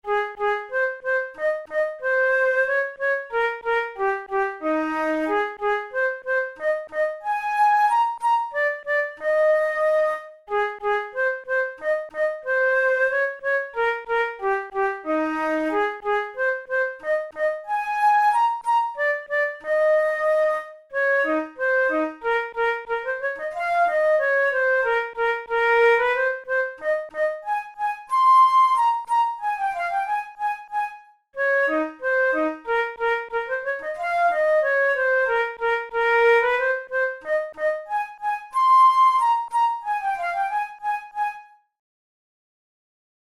InstrumentationFlute solo
KeyA-flat major
RangeEb4–C6
Time signature2/4
Tempo92 BPM
Etudes, Written for Flute